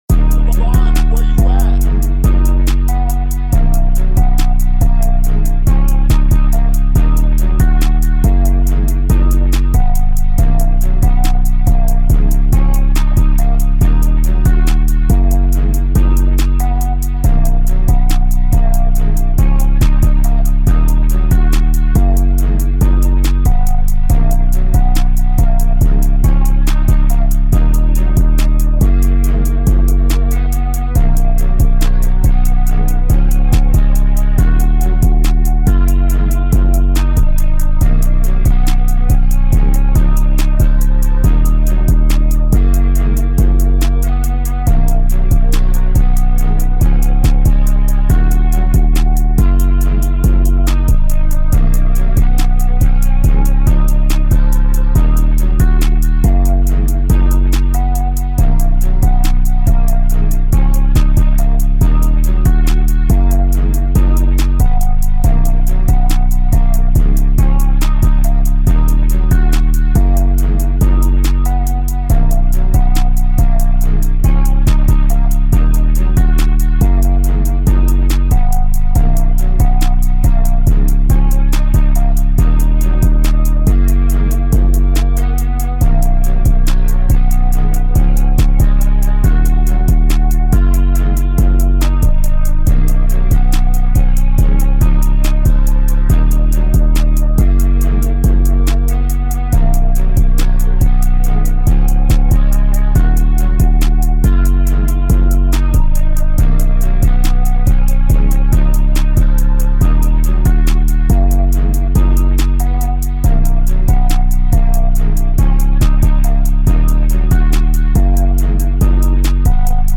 2024 in Official Instrumentals , Rap Instrumentals